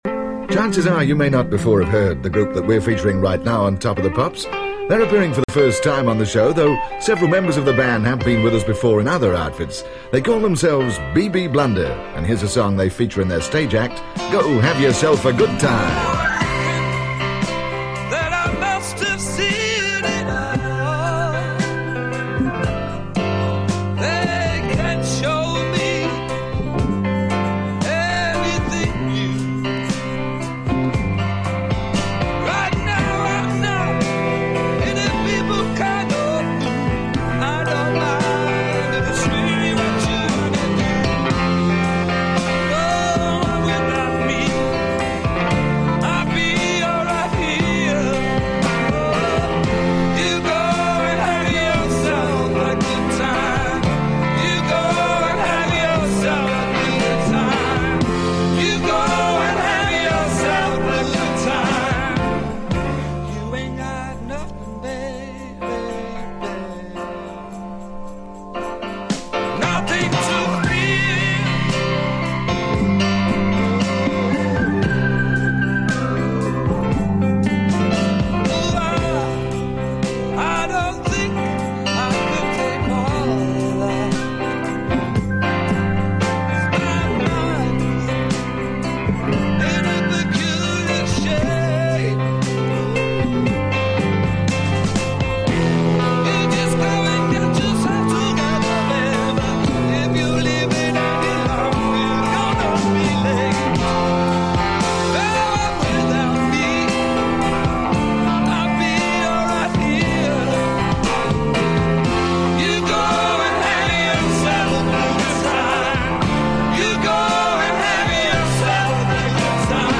in Session